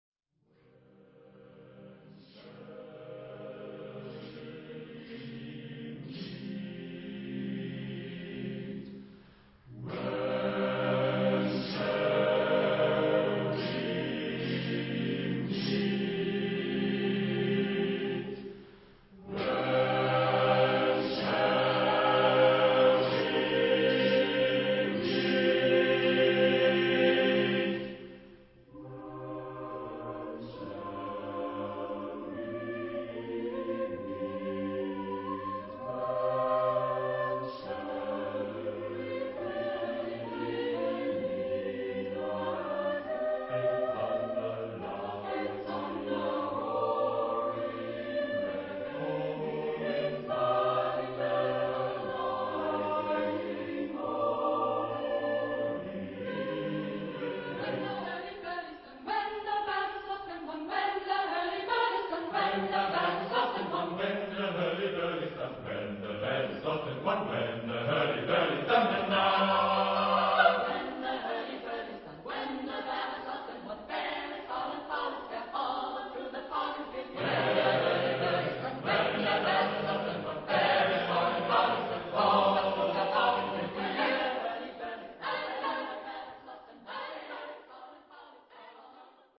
Genre-Style-Form: Vocal piece ; Secular
Type of Choir: SSA + SSA + TBB  (9 Triple choir voices )
Tonality: C minor
Rock & Jazzchor